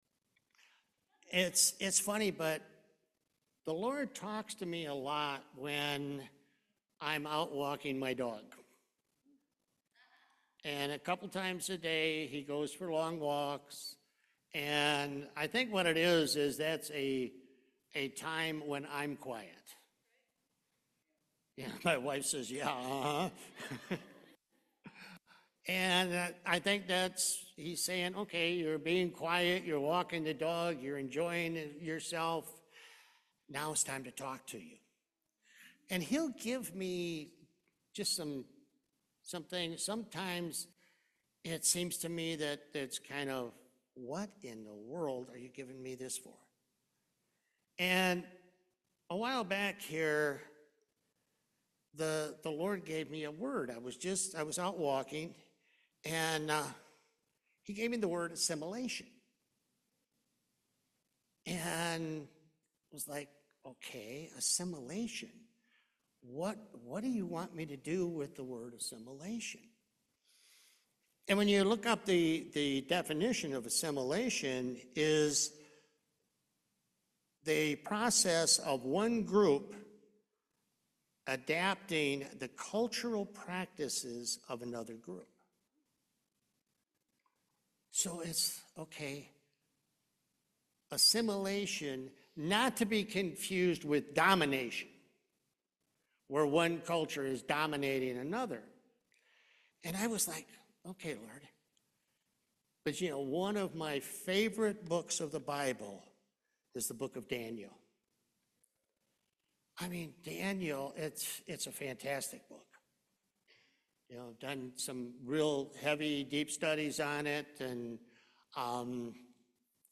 Acts 1:8 Service Type: Main Service We are to be in the world